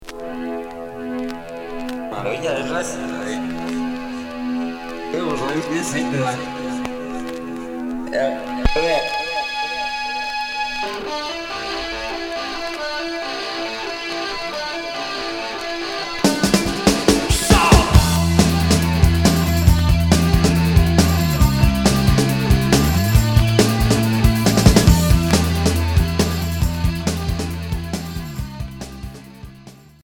Indie pop